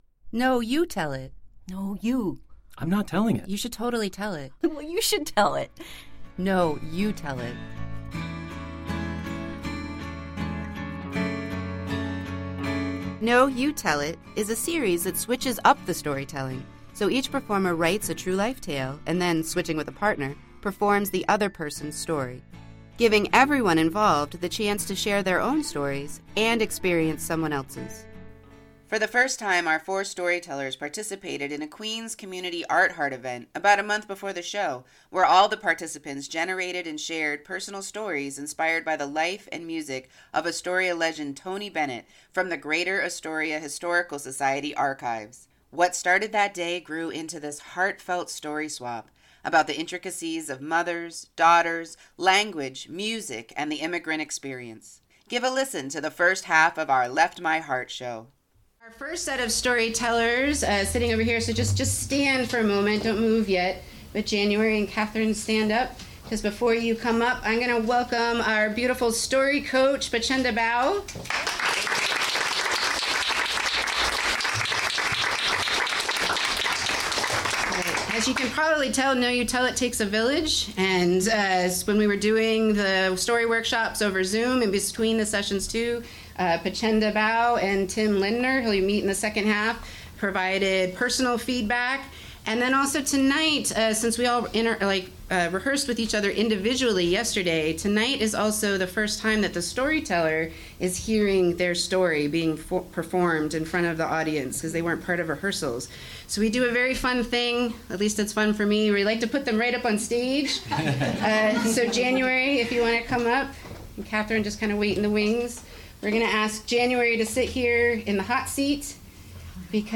Switched-Up Storytelling Series and Podcast
Give a listen to the first half of our “Left My Heart” show performed at Grove 34 on June 5, 2024.